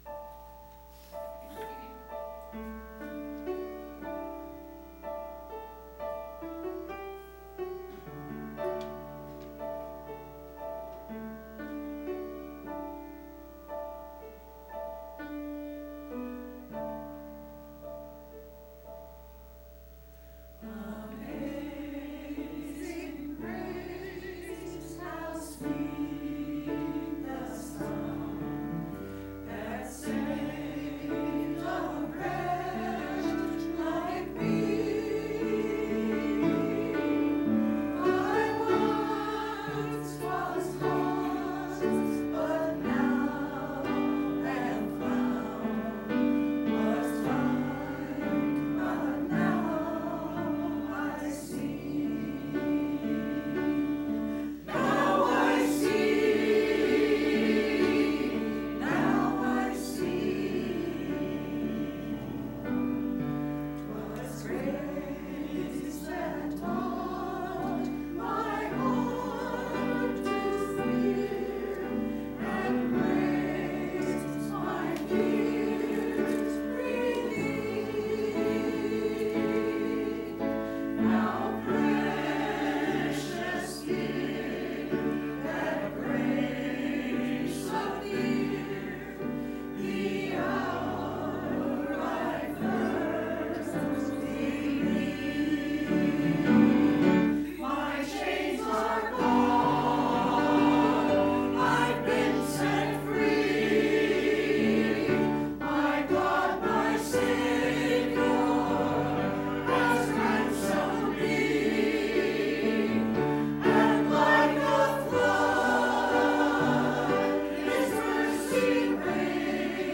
Choir Amazing Grace 02.09.20
To hear the church choir praise God with music please click play below.